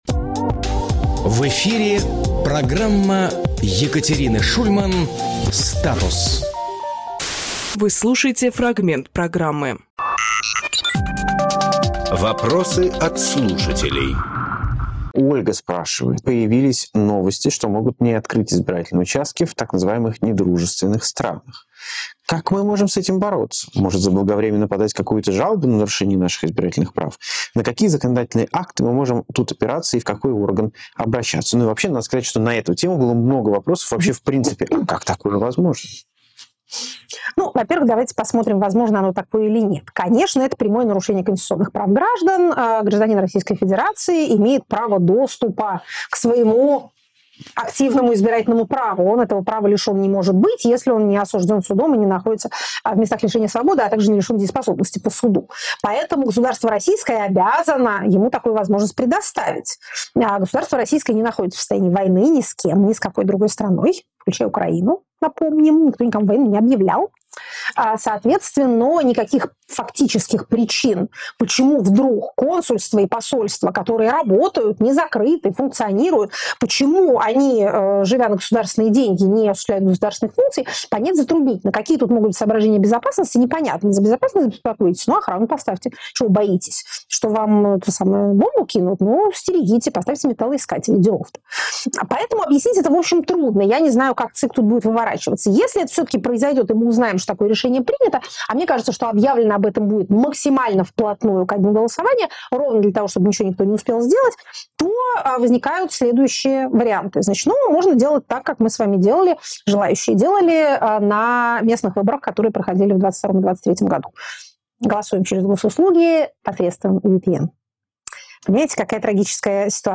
Екатерина Шульманполитолог
Максим Курниковглавный редактор «Эха», журналист
Фрагмент эфира от 09.01